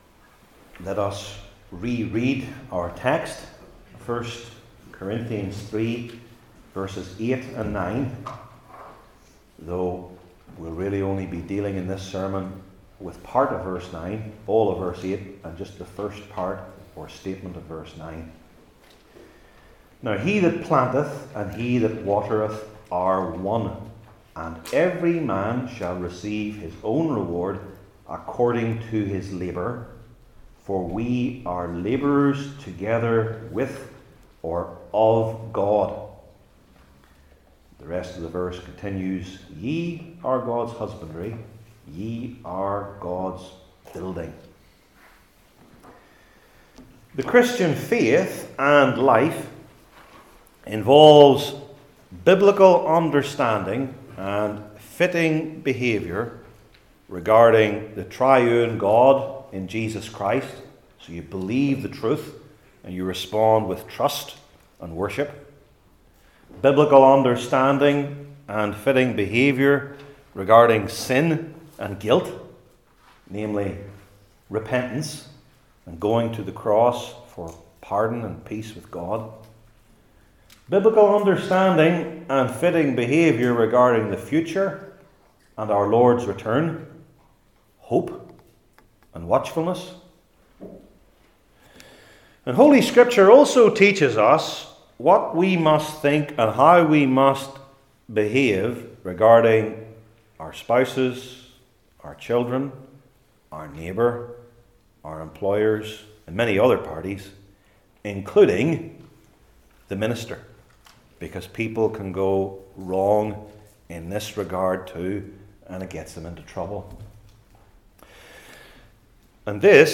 Service Type: New Testament Sermon